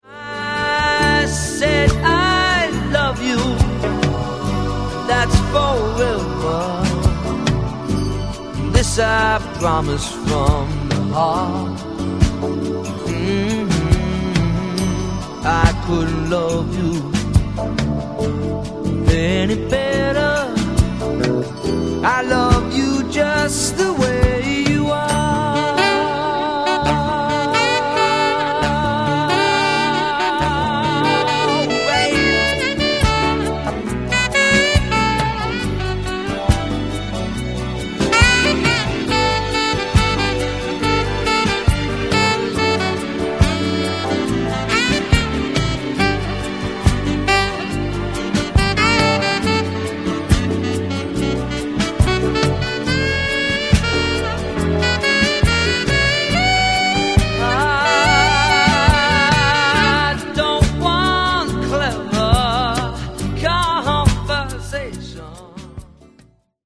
splendido assolo di sax